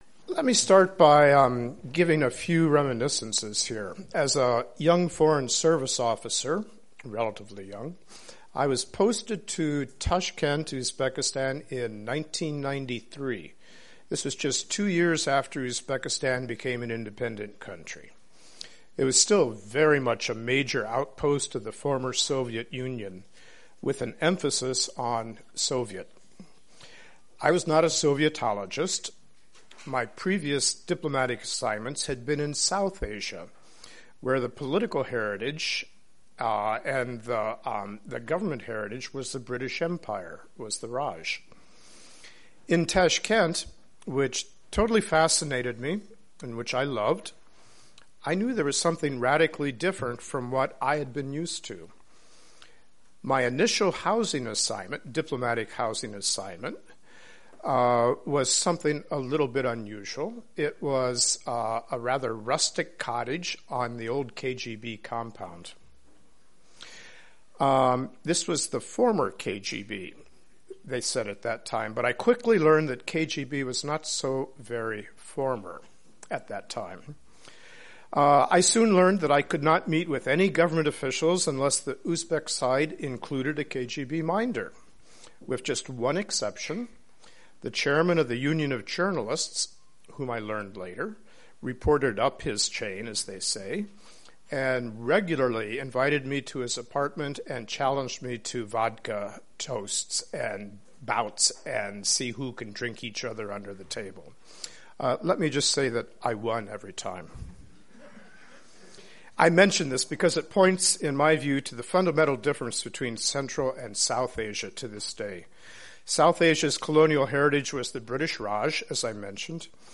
Remarks by Richard E. Hoagland, Principal Deputy Assistant Secretary, Bureau of South and Central Asian Affairs, given at Georgetown University, Washington, DC, March 30, 2015. Listen to the Q&A that follows...